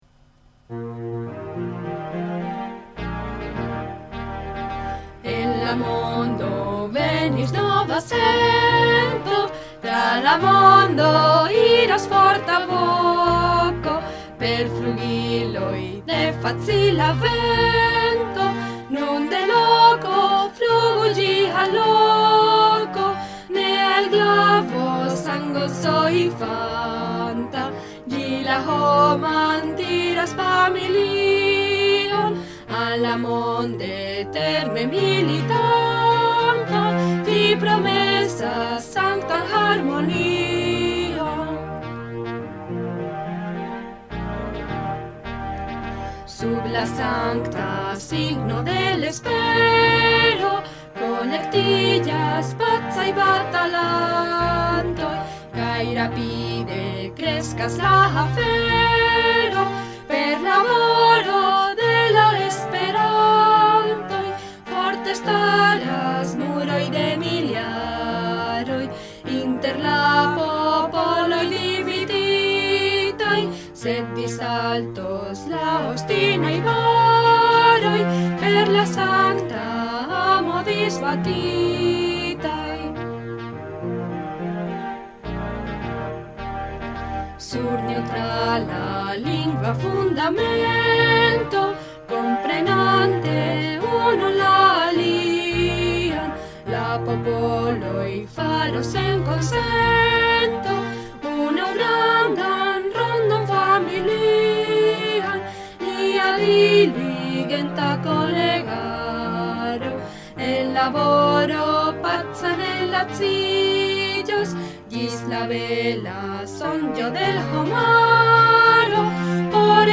La espero, himno de Esperanto verkita de L. Zamenhof kaj muzikigita de la Baron' de Ménil.
Kantite de du simpatiantinoj de Esperanto, kiuj deziras resti anonimaj.